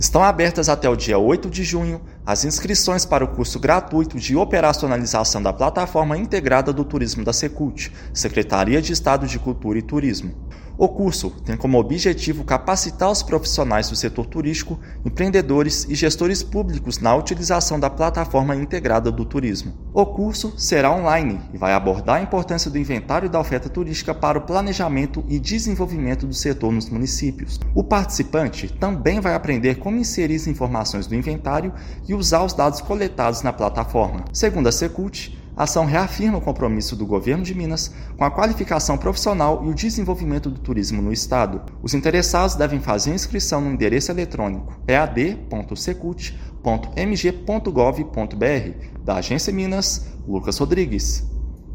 [RÁDIO] Governo de Minas oferece mil vagas no curso Operacionalização da Plataforma Integrada do Turismo
A carga horária é de 30 horas, distribuídas em três módulos ao longo de quatro semanas, com direito a certificação. Ouça matéria de rádio.